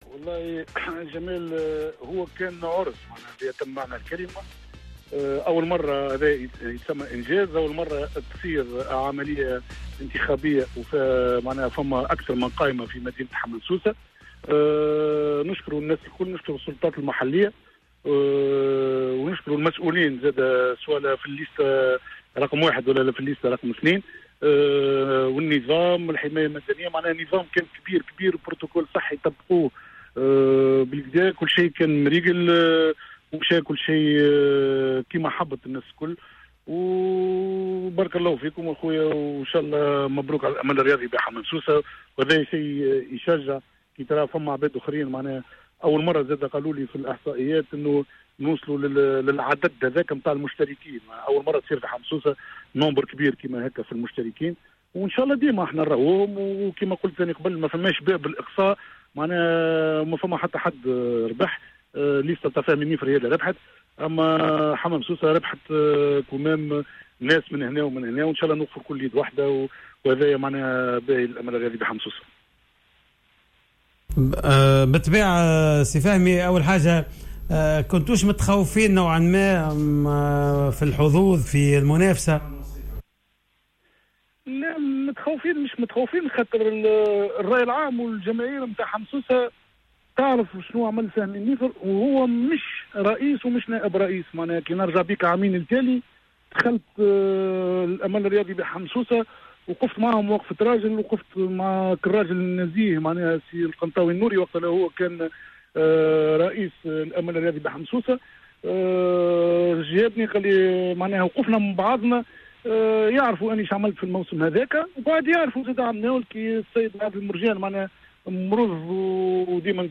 الحوار الكامل